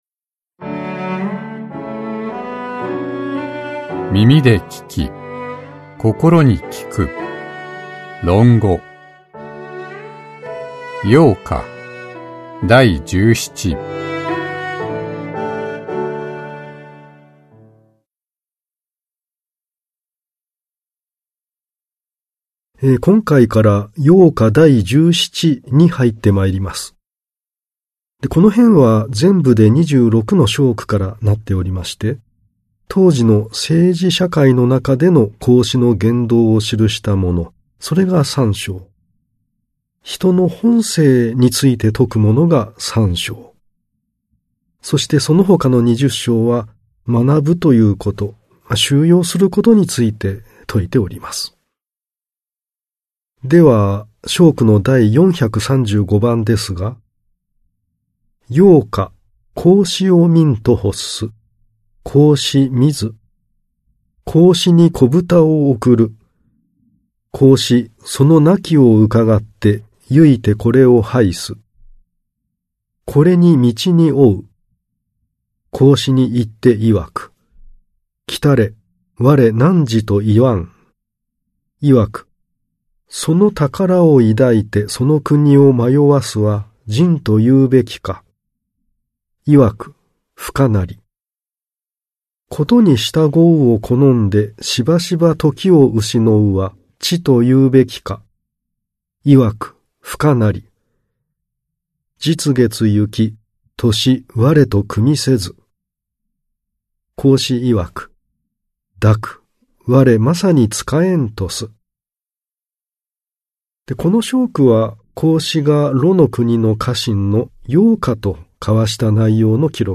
[オーディオブック] 耳で聴き 心に効く 論語〈陽貨第十七〉